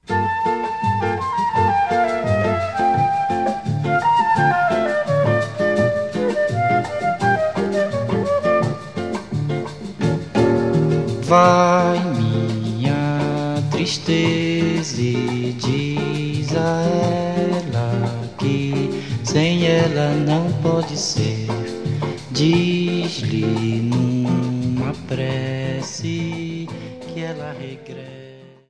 Parça ilk Bossa Nova örneğidir.